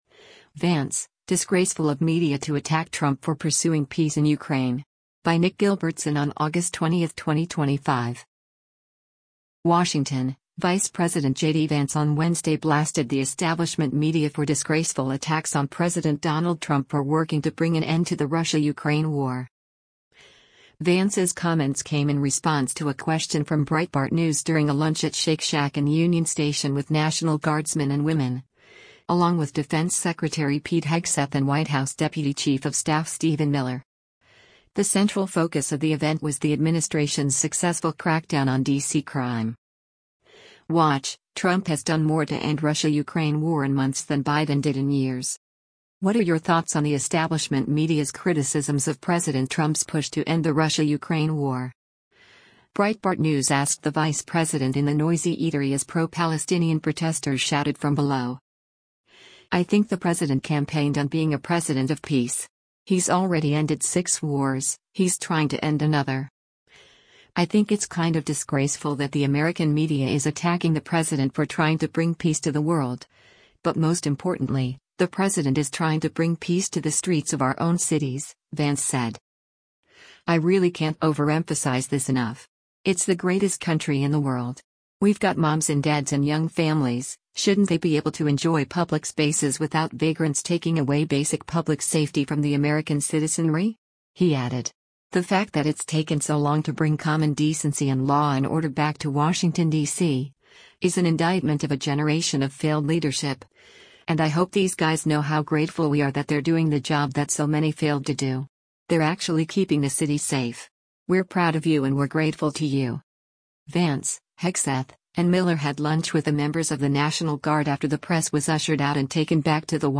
Vance’s comments came in response to a question from Breitbart News during a lunch at Shake Shack in Union Station with national guardsmen and women, along with Defense Secretary Pete Hegseth and White House Deputy Chief of Staff Stephen Miller.
“What are your thoughts on the establishment media’s criticisms of President Trump’s push to end the Russia-Ukraine war?” Breitbart News asked the vice president in the noisy eatery as pro-Palestinian protesters shouted from below.